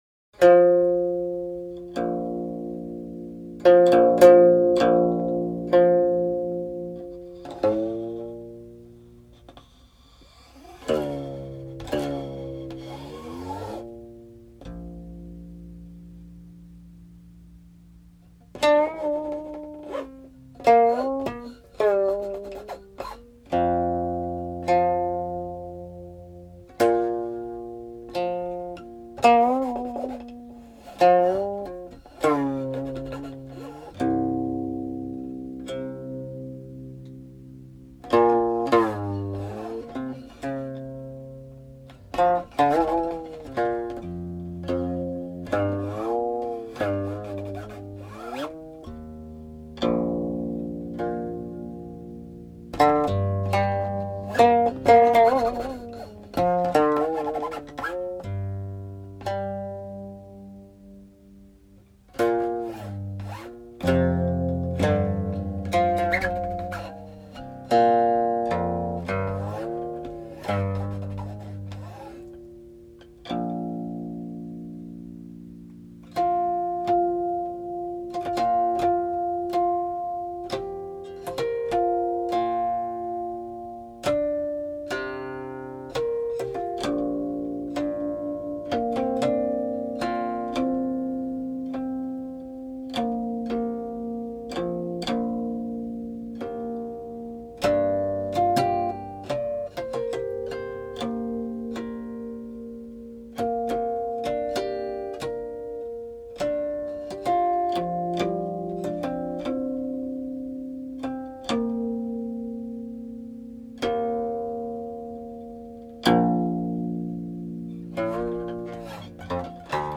Three sections, untitled13
(03.18) -- harmonics